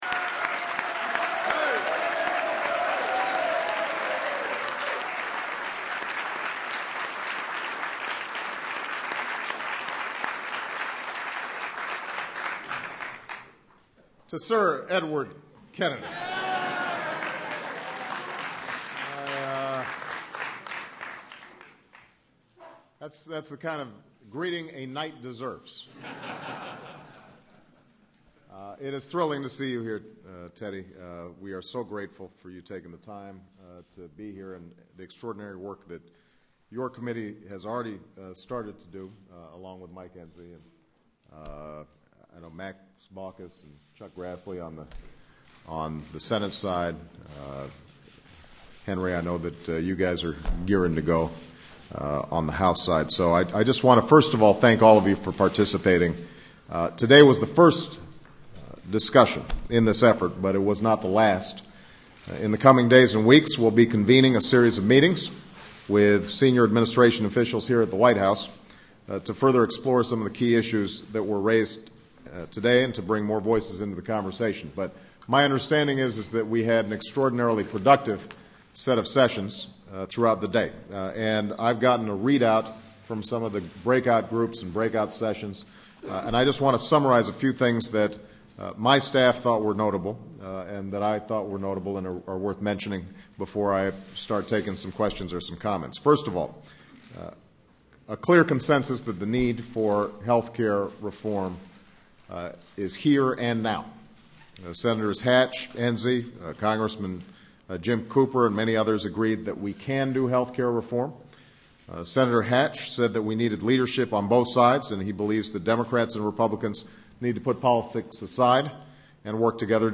U.S. President Barack Obama gives the closing remarks to the bipartisan White House Health Care Summit